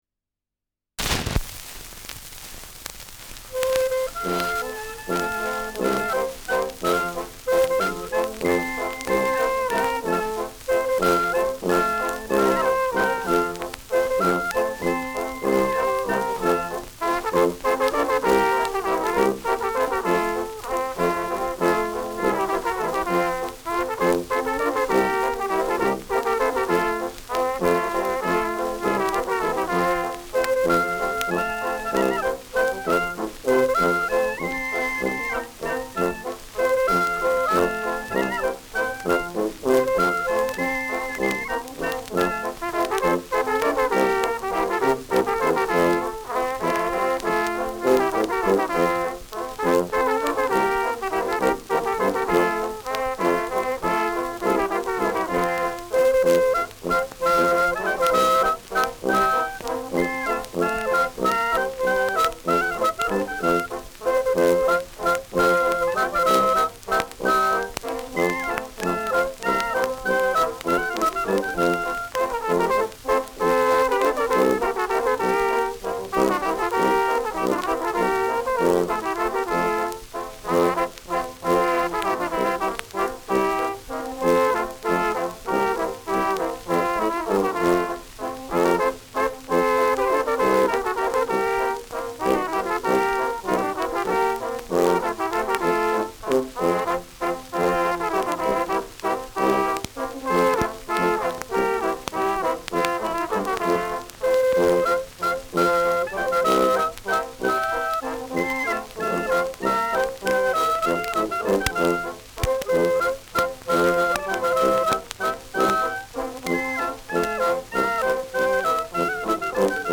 Schellackplatte
Stärkeres Grundrauschen : Gelegentlich leichtes bis stärkeres Knacken
[Nürnberg?] (Aufnahmeort)